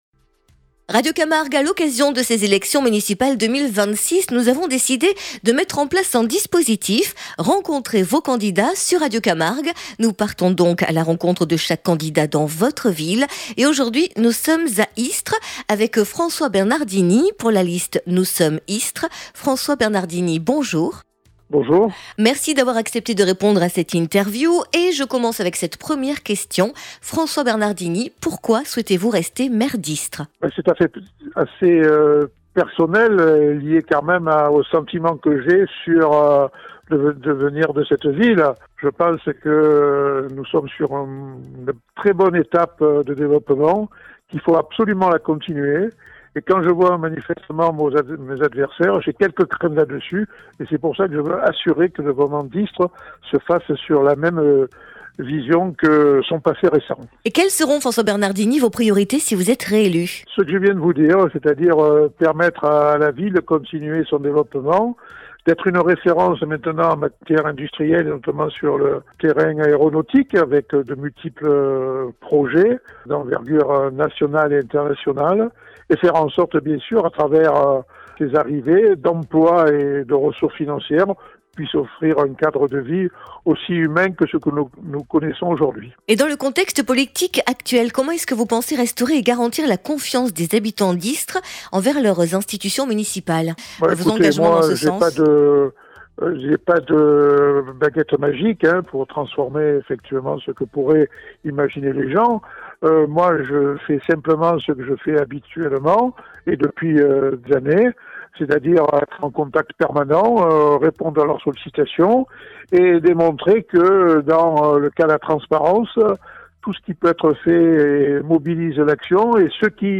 Municipales 2026 : entretien avec François Bernardini
Aujourd’hui, nous avons reçu François Bernardini, maire sortant d’Istres, dans le cadre de notre émission spéciale consacrée aux élections municipales 2026.